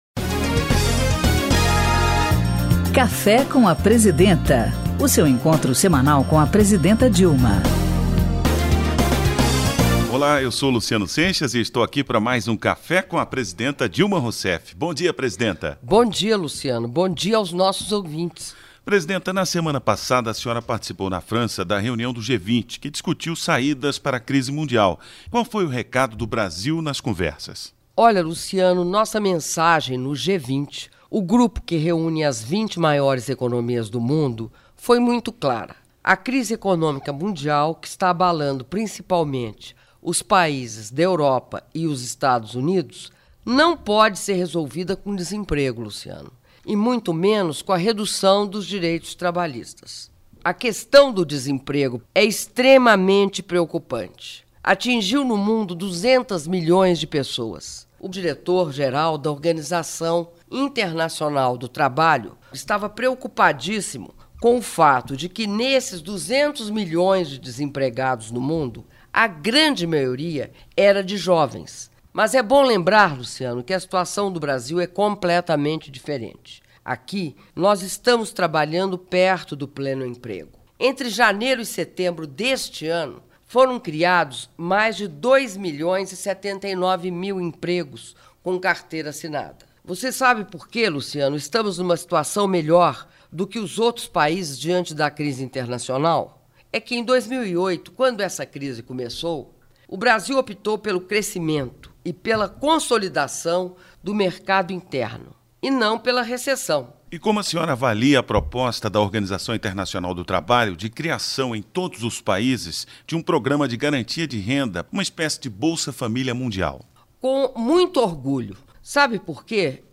Programa de rádio “Café com a Presidenta”, com a Presidenta da República, Dilma Rousseff